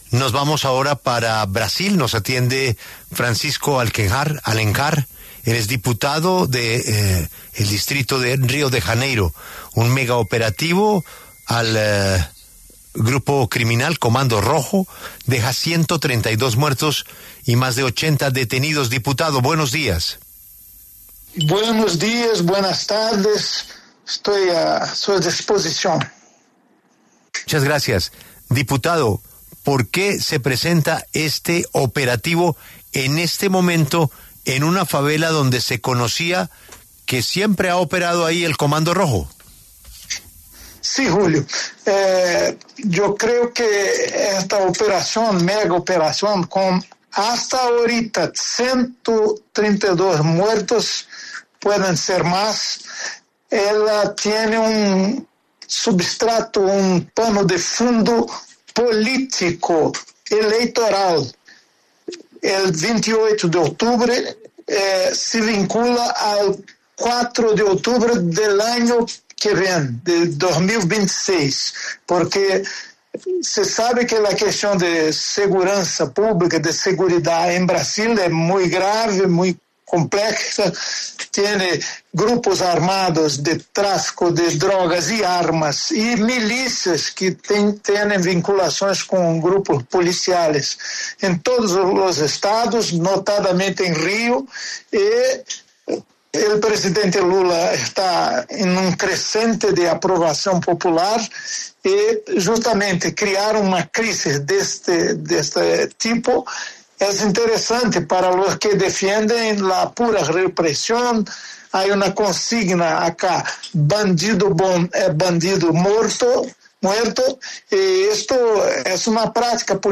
Francisco Alencar, diputado federal de Río de Janeiro, habló con La W sobre la mega operación de la Policía de Brasil en las favelas de esa ciudad y que dejó más de 130 muertos.